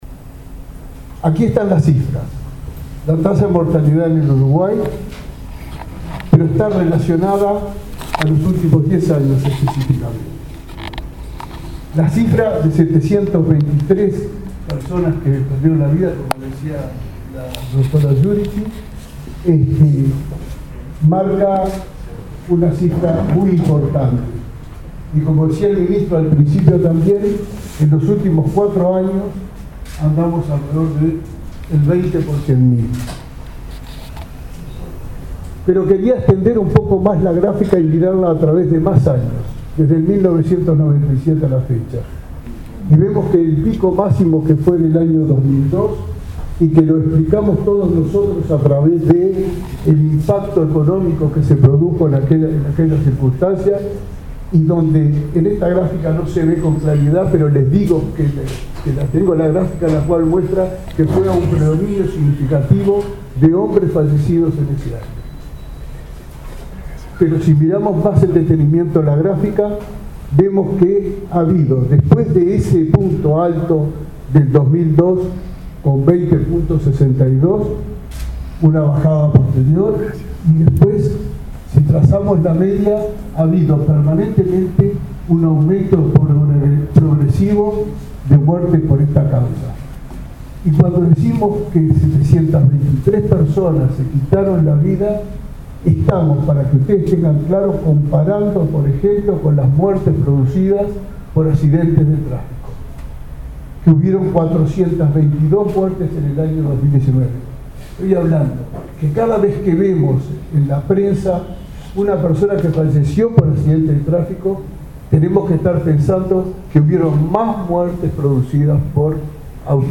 Muchas veces, el suicidio trata sobre situaciones que pueden prevenirse mediante una comunicación empática, activa y con especial observación de las modificaciones de conducta de las personas, dijo el director nacional de Salud Mental, Horacio Porciúncula, quien también llamó a terminar con el mito de que el intento de autoeliminación es un llamado de atención.